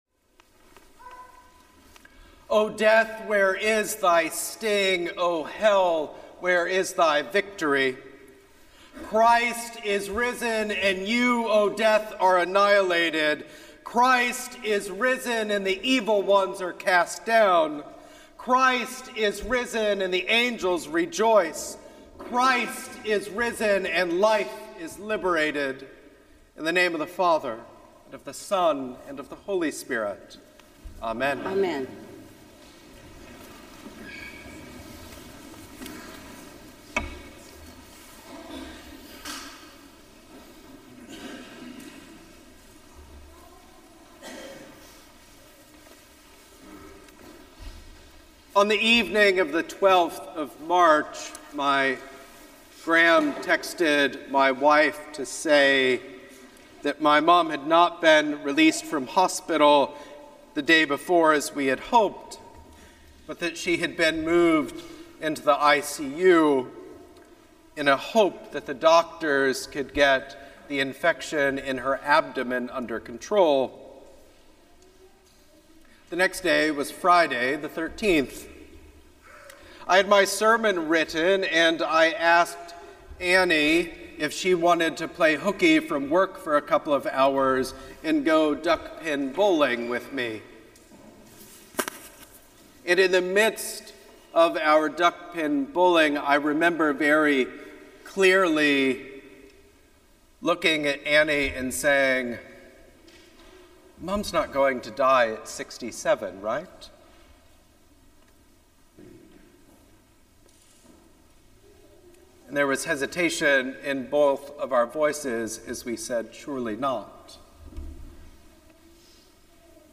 Sermons from St. Paul’s Episcopal Church, Cleveland Heights, Ohio